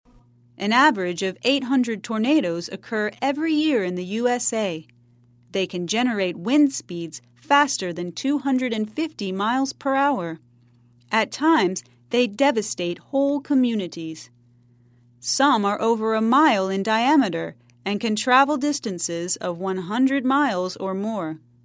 在线英语听力室赖世雄英语新闻听力通 第88期:美国年均八百个龙卷风的听力文件下载,本栏目网络全球各类趣味新闻，并为大家提供原声朗读与对应双语字幕，篇幅虽然精短，词汇量却足够丰富，是各层次英语学习者学习实用听力、口语的精品资源。